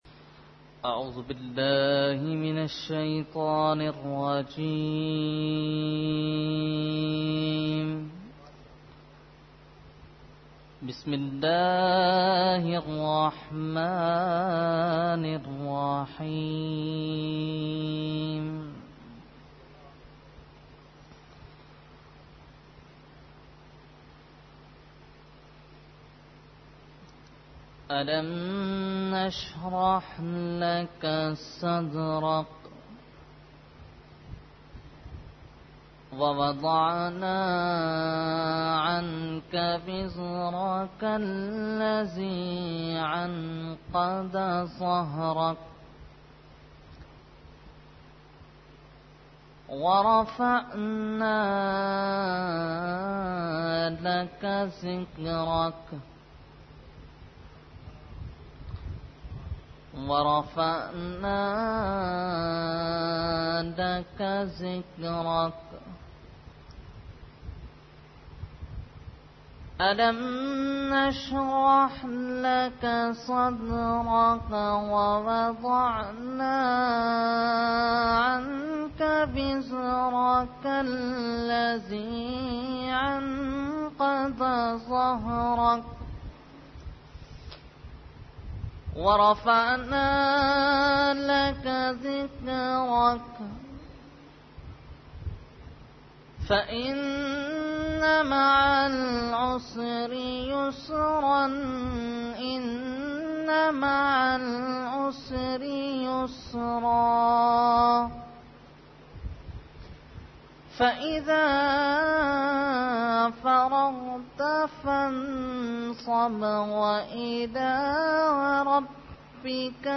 Qirat – Memon Masjid Musleuddin Garden 2014 – Dargah Alia Ashrafia Karachi Pakistan